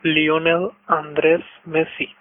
Lionel Andrés Messi (pronunciació en castellà: /ljoˈnel anˈdɾes ˈmesi/) (Rosario, 24 de juny de 1987), també conegut com a Leo Messi, és un futbolista argentí[1] que juga com a davanter i és capità de l'Inter de Miami, a la Major League Soccer, i de la selecció nacional d'Argentina.[2] Jugador històric del Futbol Club Barcelona, al qual va jugar durant vint anys,[a] fins a la seva marxa el 2021 va guanyar 35 trofeus, inclosos 10 Lligues, 7 Copes del Rei i 4 Lligues de Campions de la UEFA.
Lionel_Andrés_Messi_-_Name.ogg